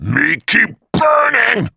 Kr_voice_kratoa_death.mp3